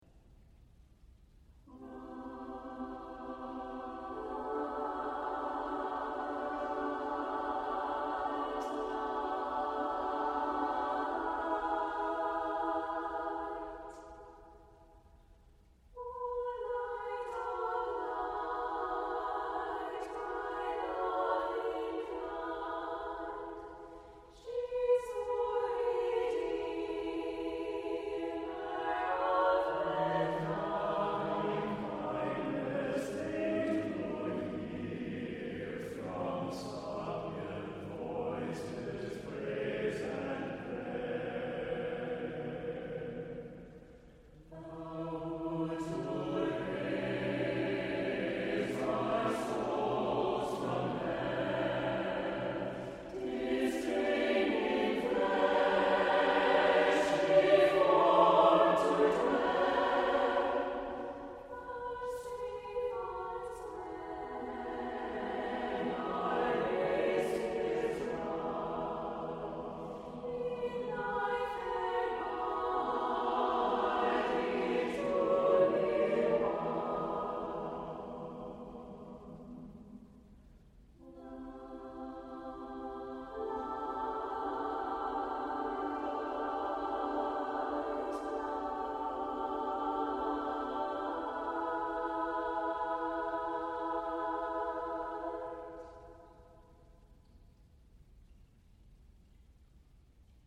• Music Type: Choral
• Voicing: SATB
• Accompaniment: a cappella
A mystical sound is created through close harmony.